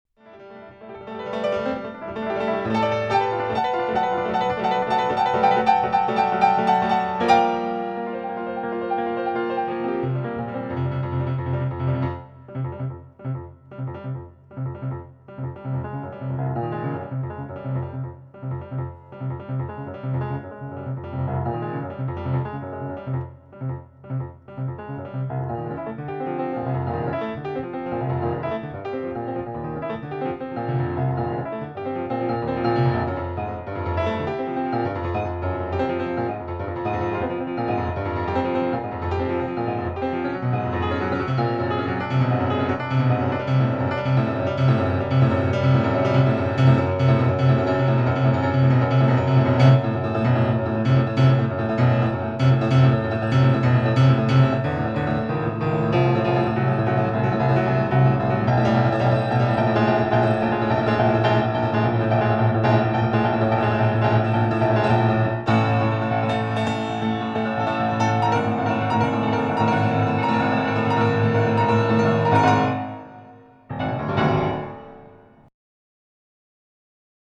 piano
Essentially a piano sonata in three short movements